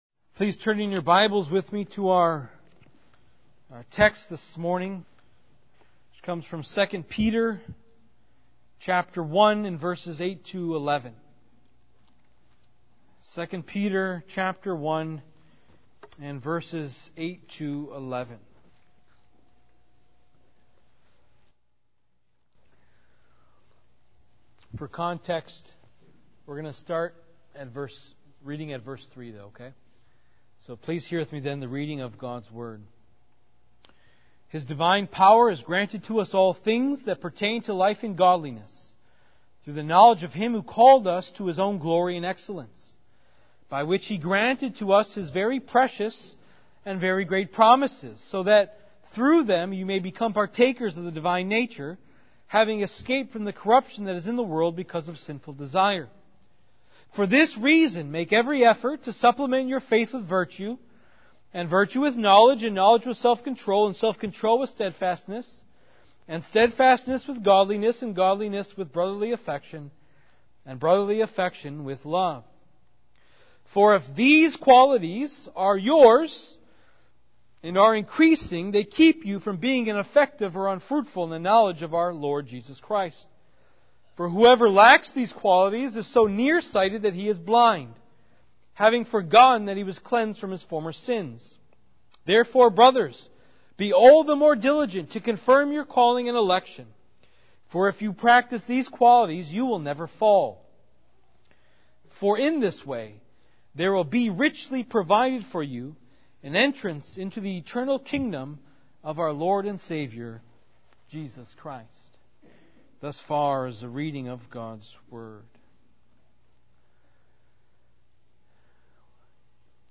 2-peter-1-8-11-sermon-confirming-your-calling-and-election.mp3